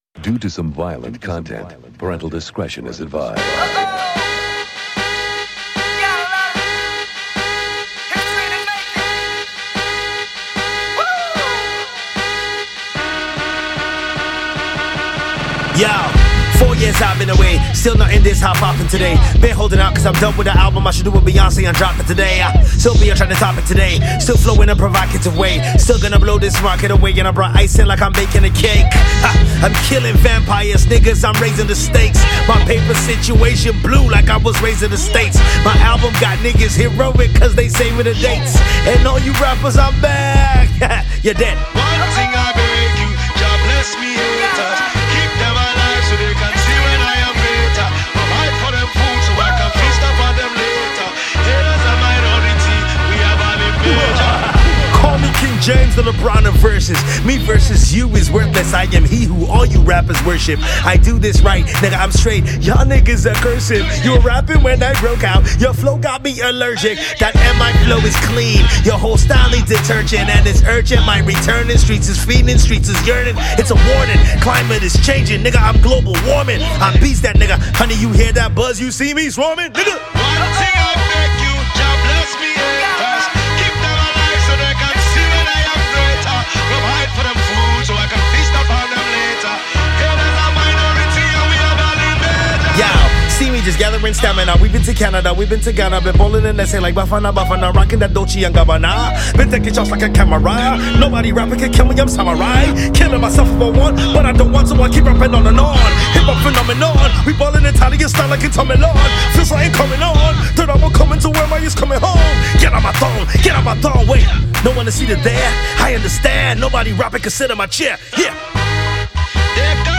Yep, we definitely missed his lyrical dexterity.